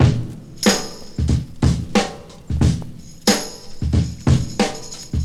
• 92 Bpm Drum Groove B Key.wav
Free breakbeat sample - kick tuned to the B note. Loudest frequency: 1448Hz
92-bpm-drum-groove-b-key-xhk.wav